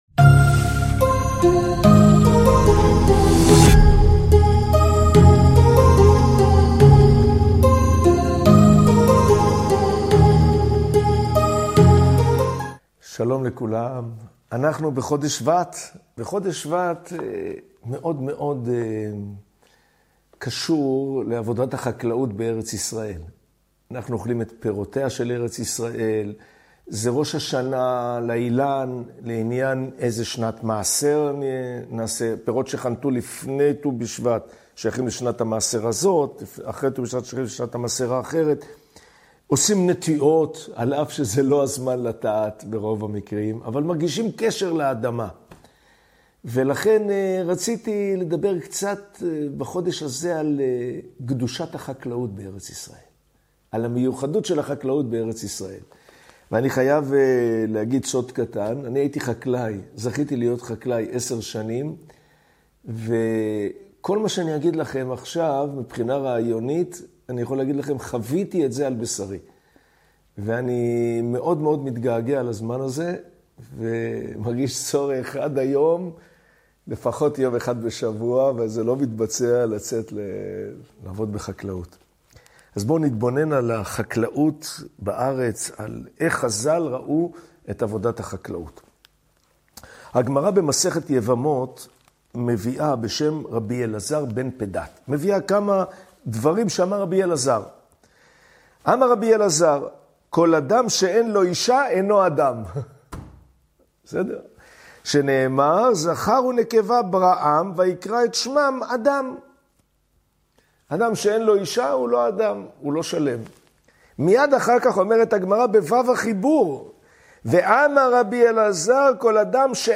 בשיעור המיוחד לחודש שבט תגלו מה החקלאות ועבודת האדמה יכולה לתת גם לאנשי הייטק, מקצועות חופשיים ואנשי חינוך. מה מיוחד כל כך בעולמם של החקלאים ולמה חשוב לכל אדם להתעסק ‘קצת’ בעבודת אדמה…